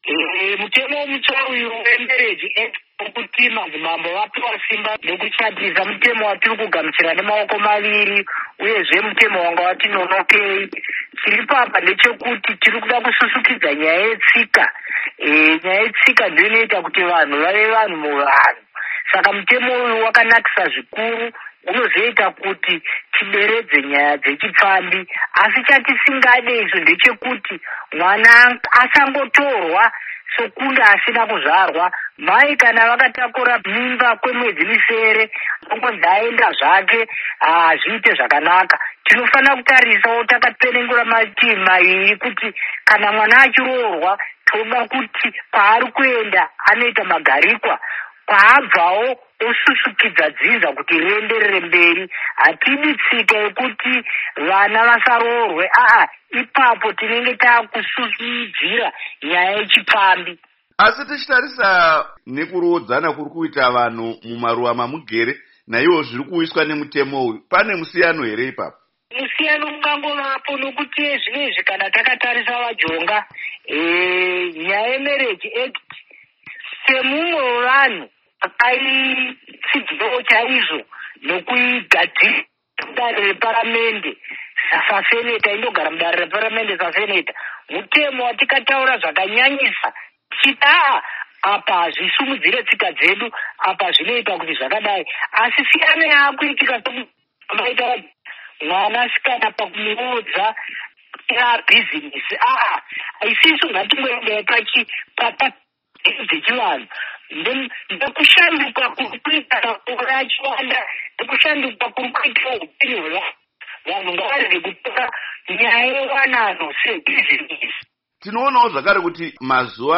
Hurukuro naIshe Nembire vari Seneta, VaClemence Nembire vekuMt. Darwin